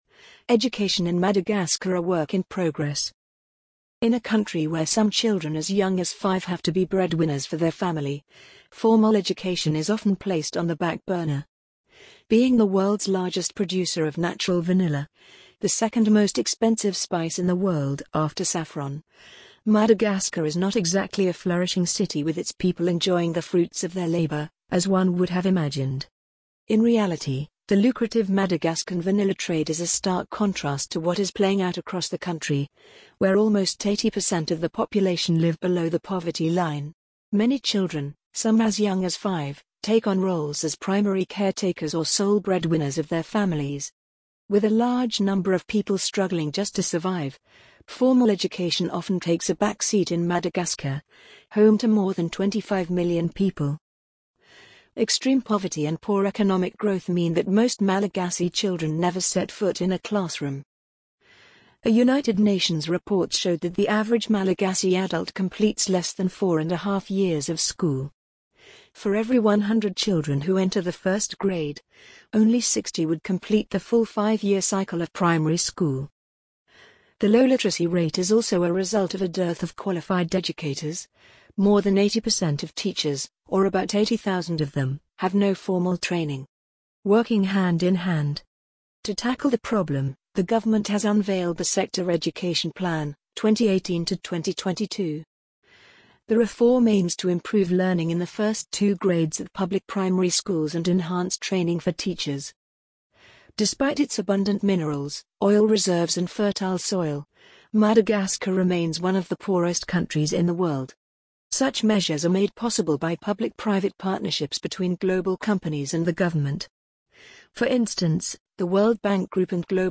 amazon_polly_2788.mp3